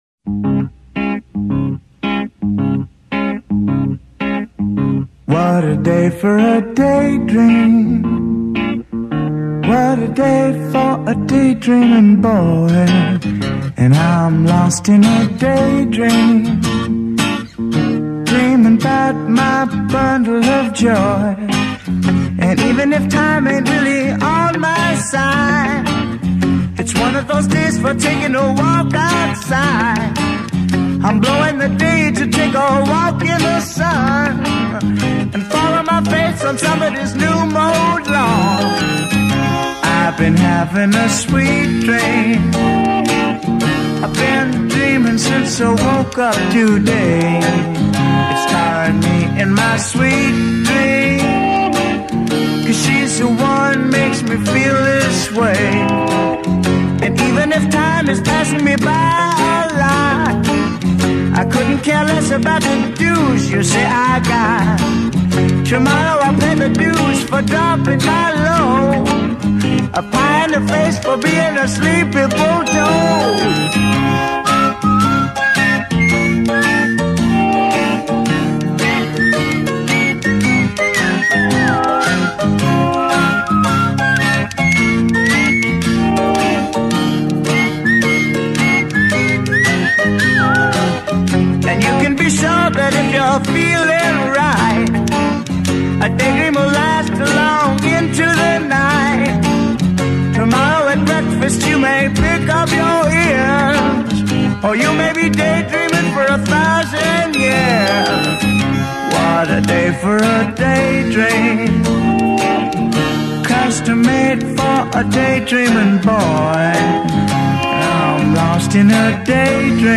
American rock band
J’aime beaucoup son intro eg sa légèreté musique.